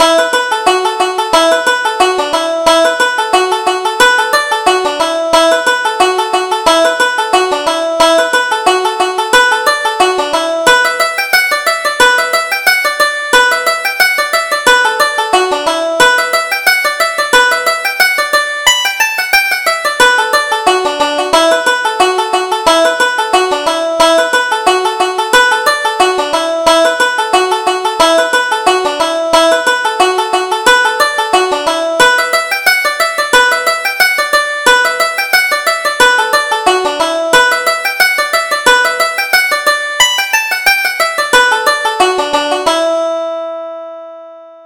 Reel: The New Potatoes